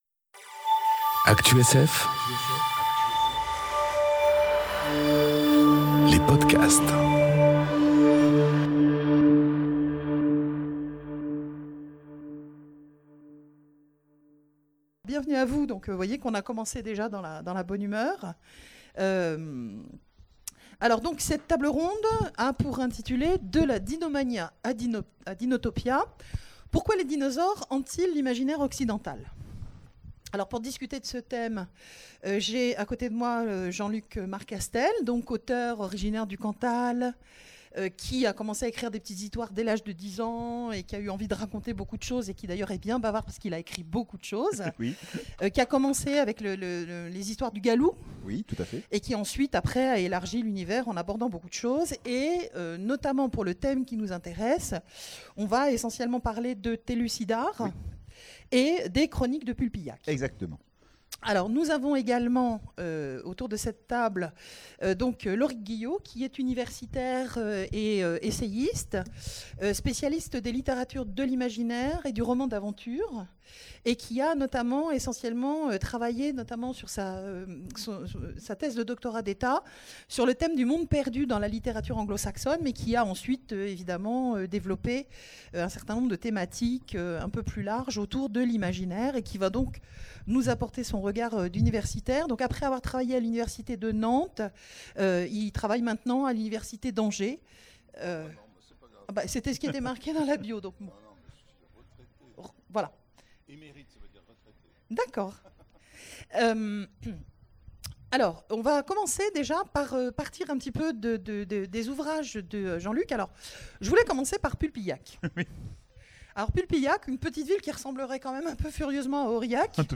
Conférence De la dinomania à Dinotopia... Pourquoi les dinosaures hantent-ils l'imaginaire occidental ? enregistrée aux Imaginales 2018